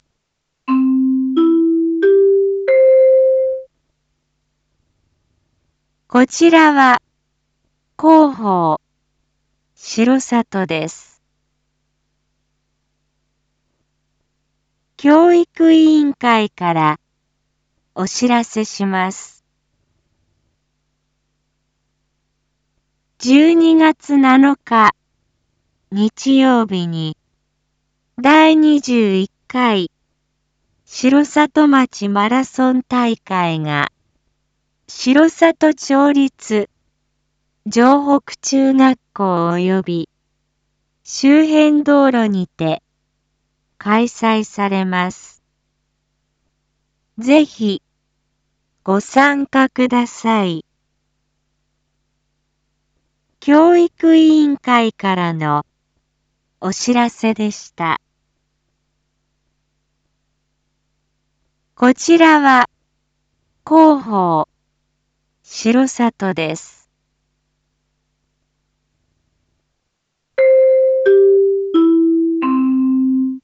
Back Home 一般放送情報 音声放送 再生 一般放送情報 登録日時：2025-10-30 19:01:14 タイトル：第21回城里町マラソン大会開催② インフォメーション：こちらは広報しろさとです。